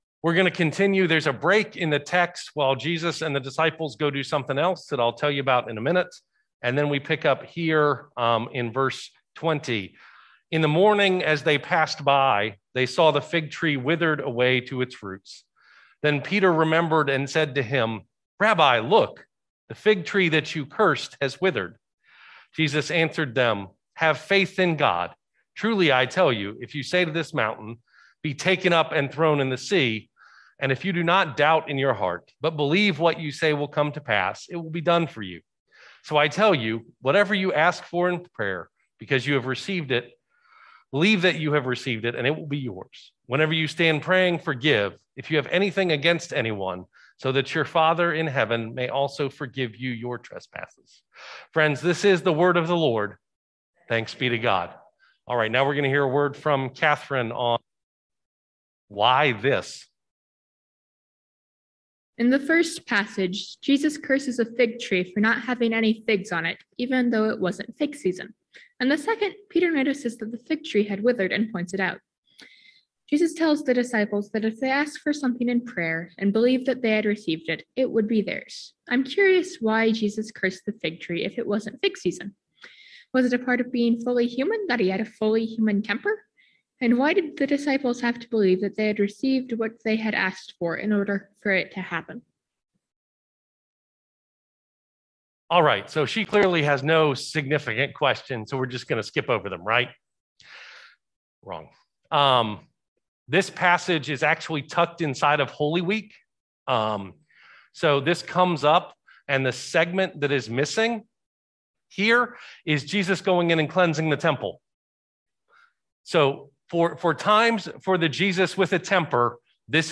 Worship Service – 10:30 AM